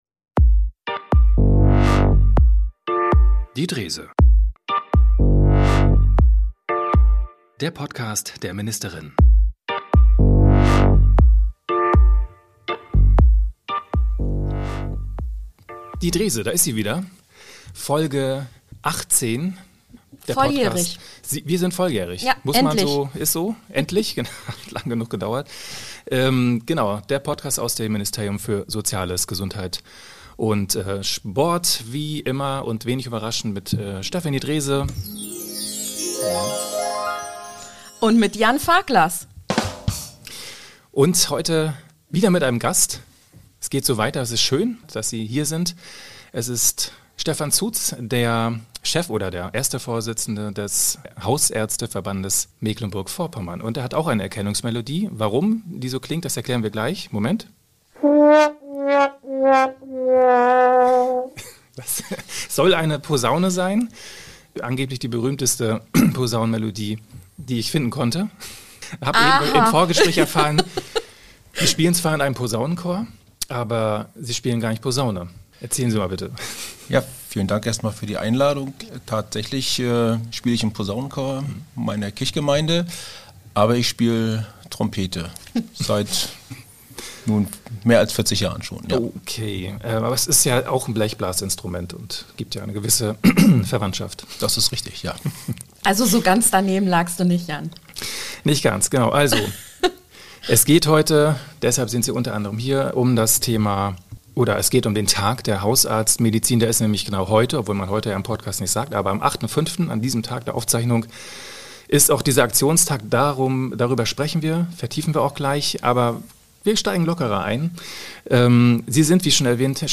Im Gespräch mit Gesundheitsministerin Stefanie Drese geht es daher um nicht weniger als die Zukunft der ambulanten hausärztlichen Versorgung im Land.